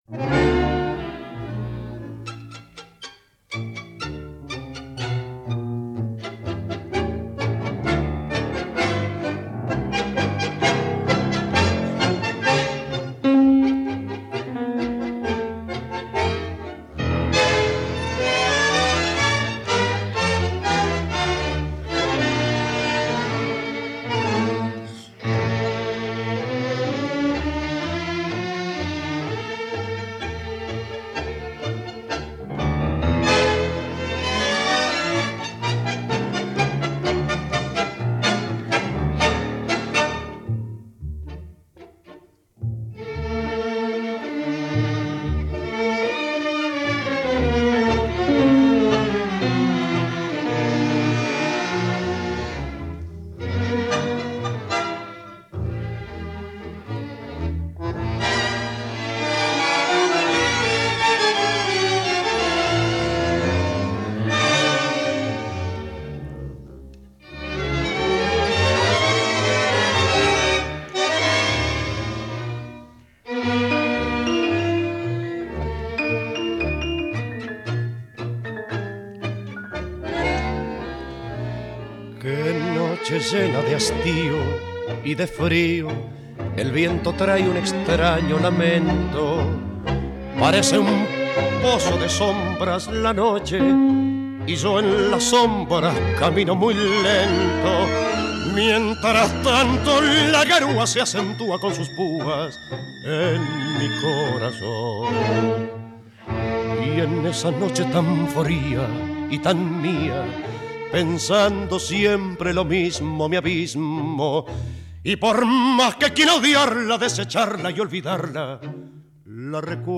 ein Sänger mit sehr ausdrucksvoller Stimme.